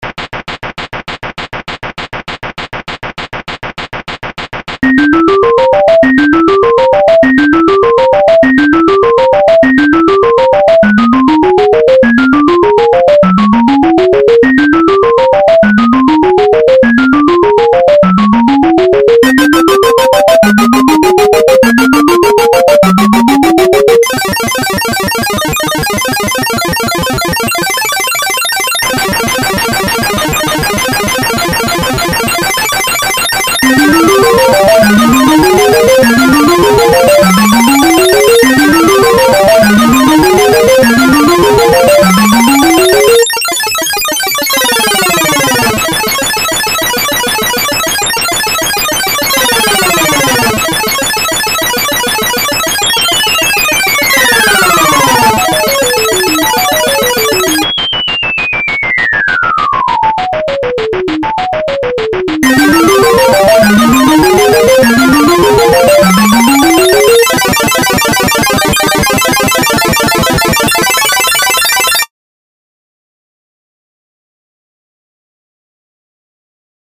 Pure NES delights.